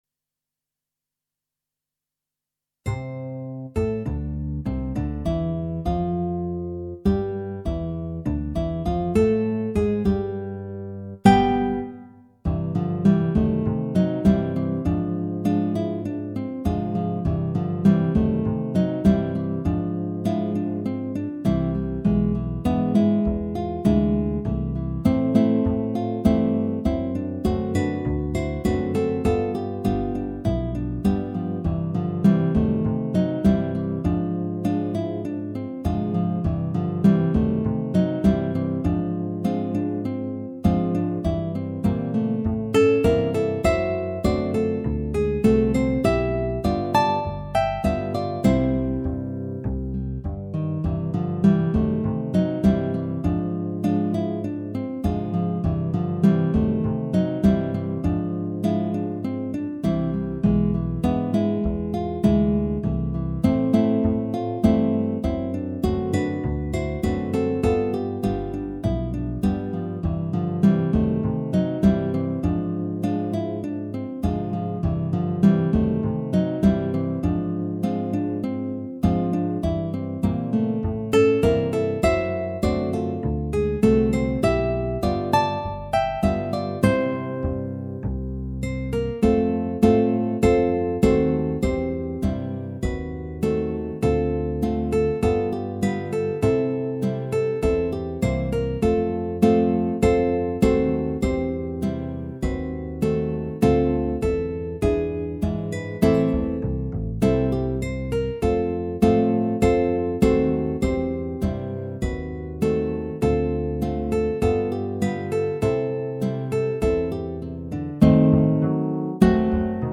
Guitar Quartet
Quartet arrangement
but the essential gaiety of the style remains.